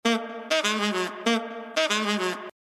D#m_-_98_-_Sax_Loop_01
sax-loops-dm.mp3